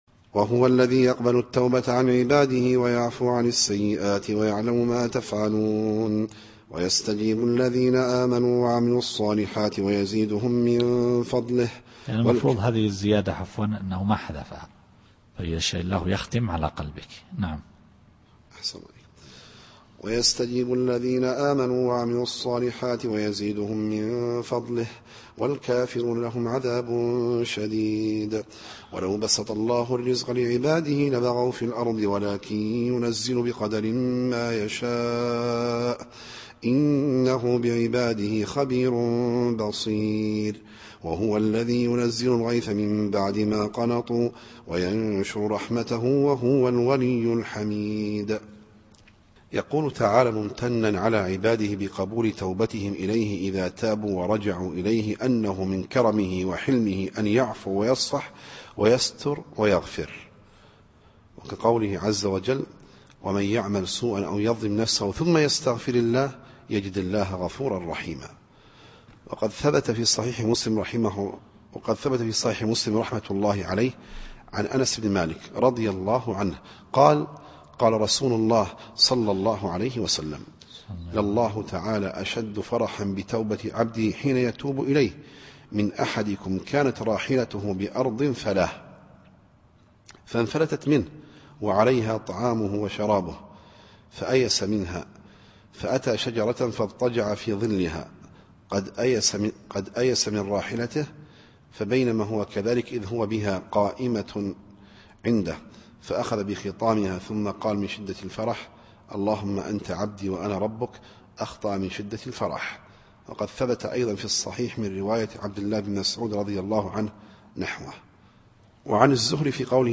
التفسير الصوتي [الشورى / 25]